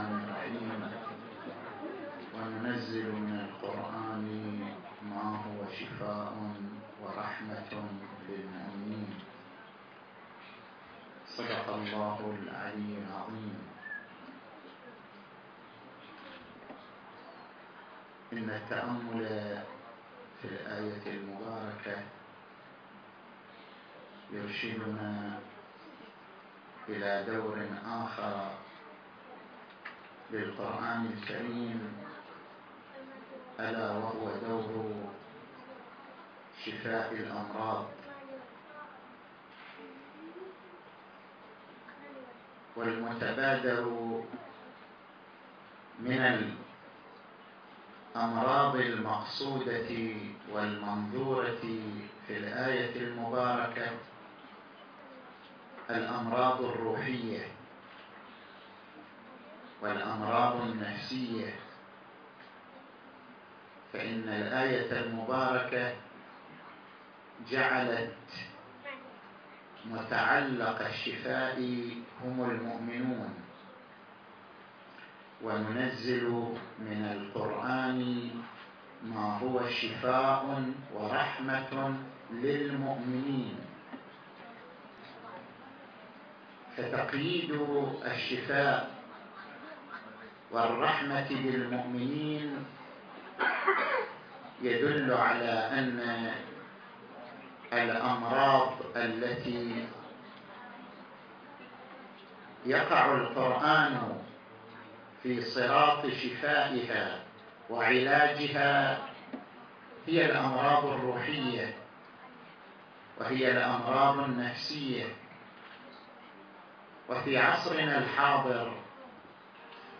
كلمة
في حفل تكريم حفاظ القرآن الكريم - حفل تكريم حفاظ القرآن الكريم 1439 لحفظ الملف في مجلد خاص اضغط بالزر الأيمن هنا ثم اختر